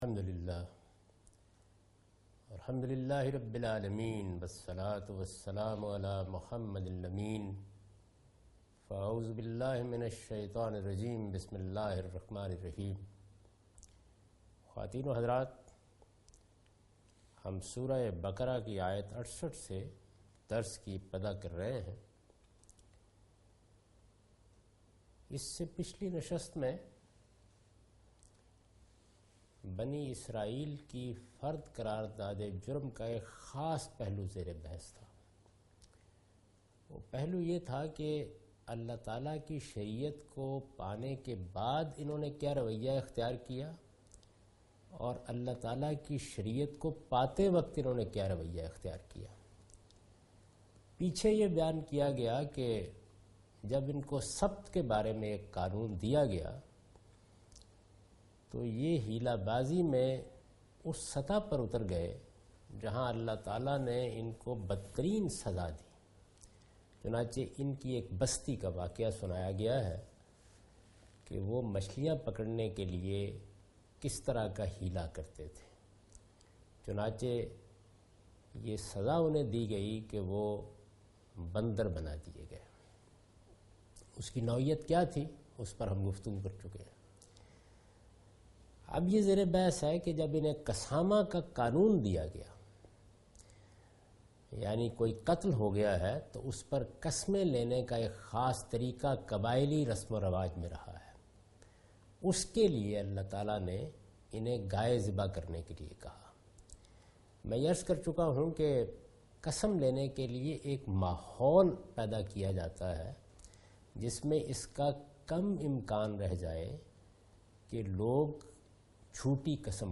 Surah Al-Baqarah - A lecture of Tafseer-ul-Quran – Al-Bayan by Javed Ahmad Ghamidi. Commentary and explanation of verse 68,69,70,71,72 and 73 (Lecture recorded on 3rd Oct 2013).